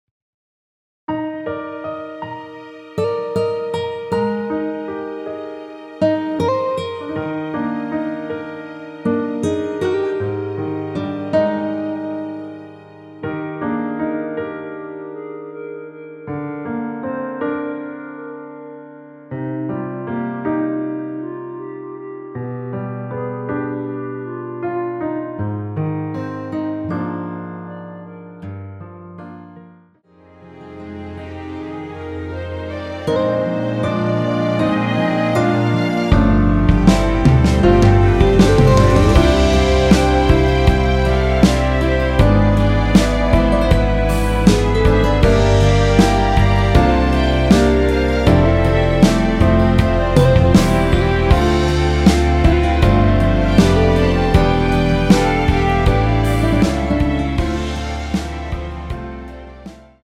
원키에서(-1)내린 (1절앞+후렴)으로 진행되는 멜로디 포함된 MR입니다.
Eb
앞부분30초, 뒷부분30초씩 편집해서 올려 드리고 있습니다.
중간에 음이 끈어지고 다시 나오는 이유는